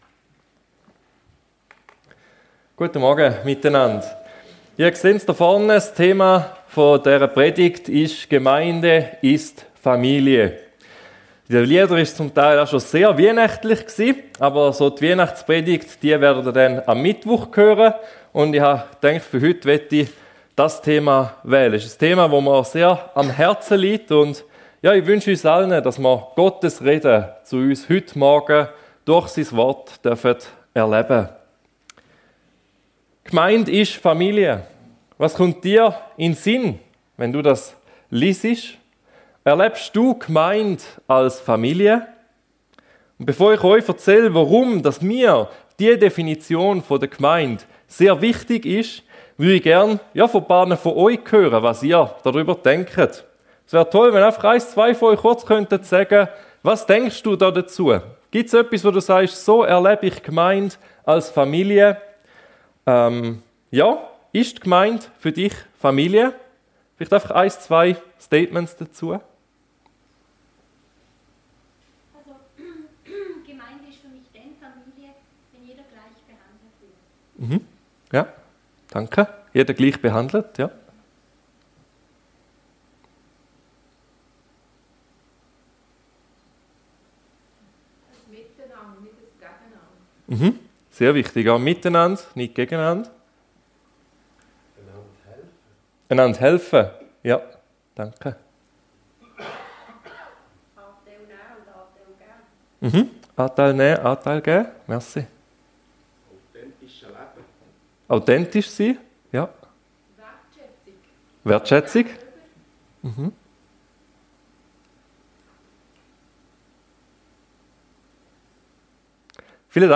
Gemeinde ist Familie ~ FEG Sumiswald - Predigten Podcast